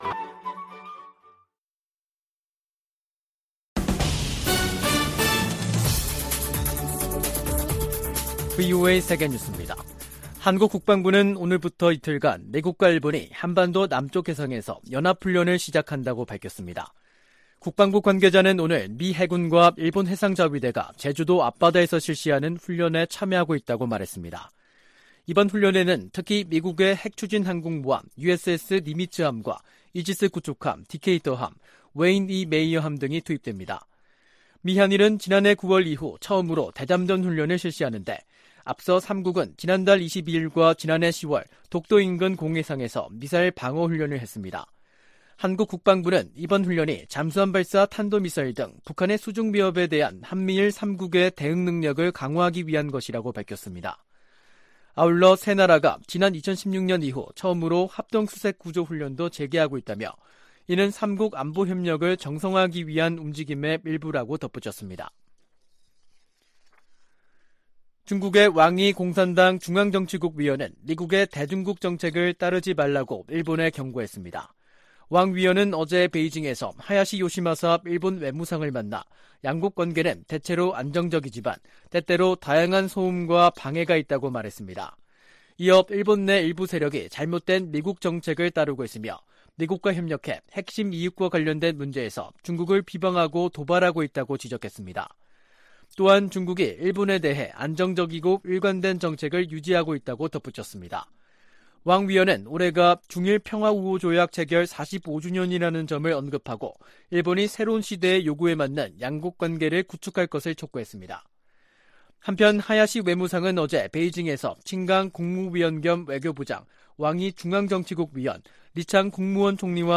VOA 한국어 간판 뉴스 프로그램 '뉴스 투데이', 2023년 4월 3일 2부 방송입니다. 미 상원이 대통령 무력사용권 공식 폐지 법안을 가결했습니다.